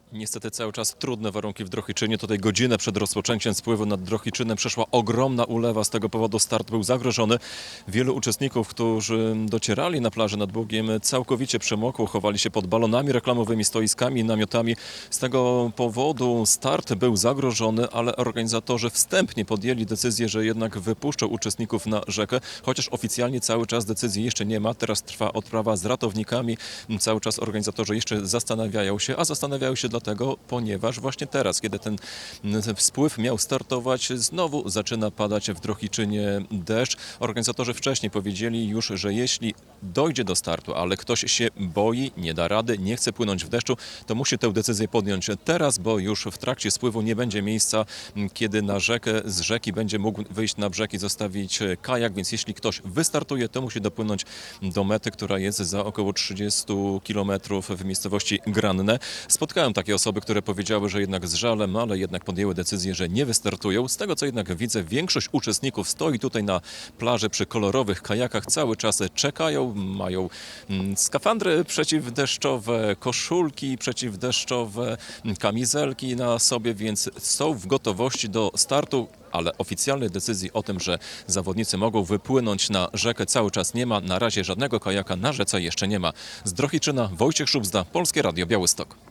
Największy polski spływ kajakowy pod znakiem zapytania z powodu warunków atmosferycznych - relacja